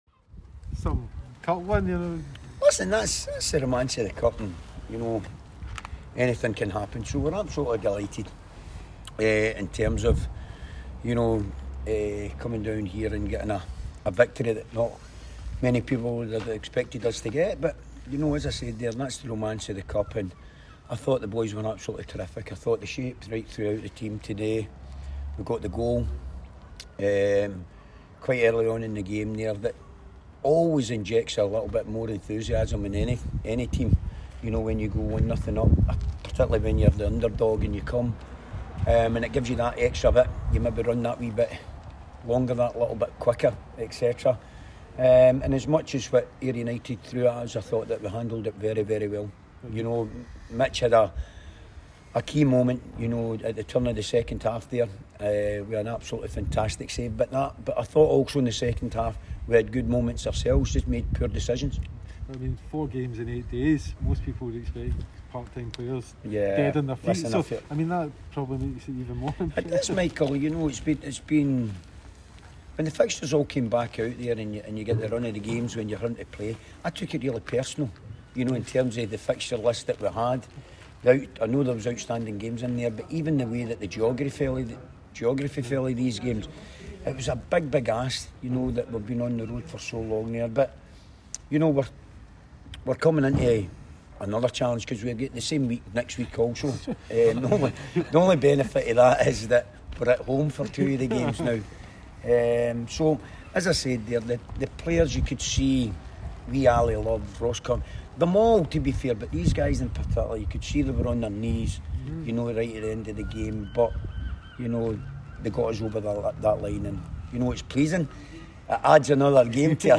press conference after the Scottish Cup match.